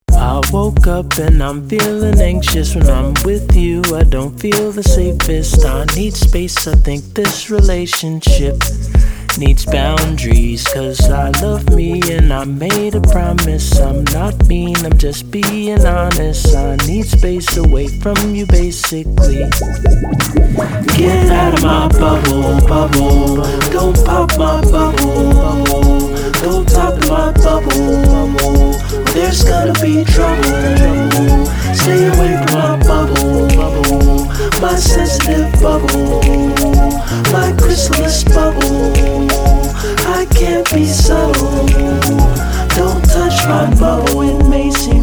Hip Hop, soul and electronic